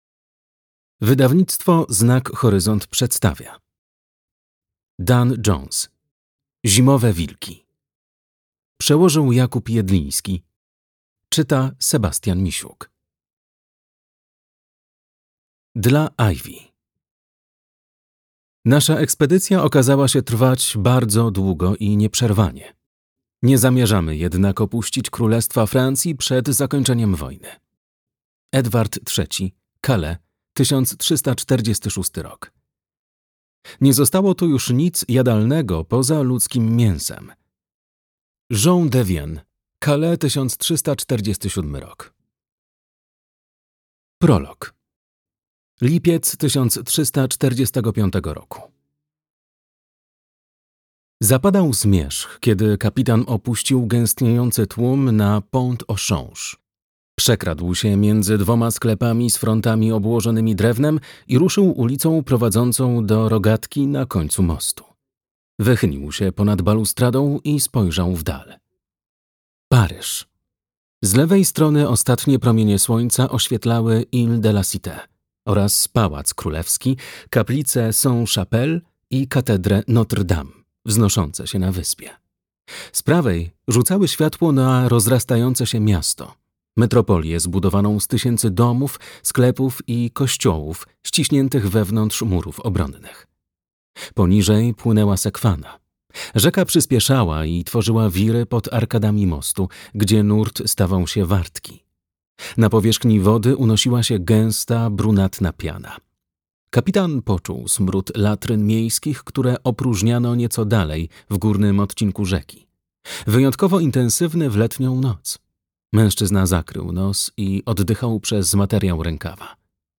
Zimowe wilki - Jones Dan - audiobook